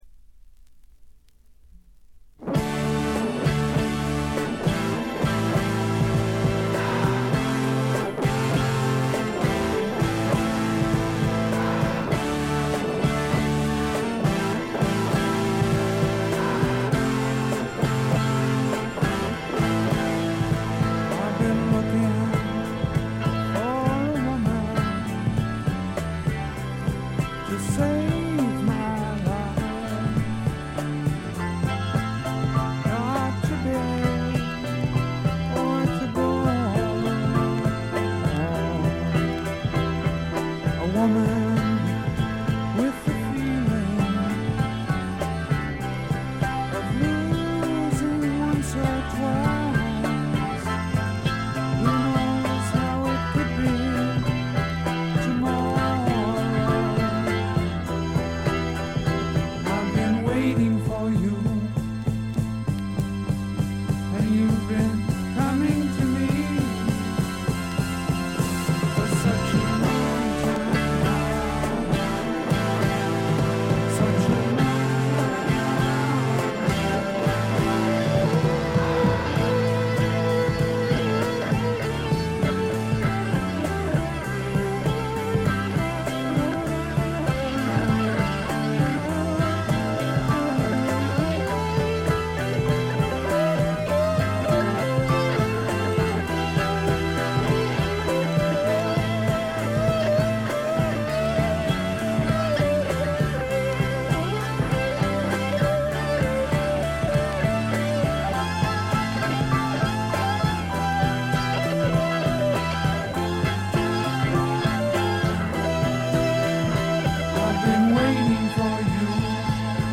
静音部ところどころでチリプチ。散発的なプツ音少々。
試聴曲は現品からの取り込み音源です。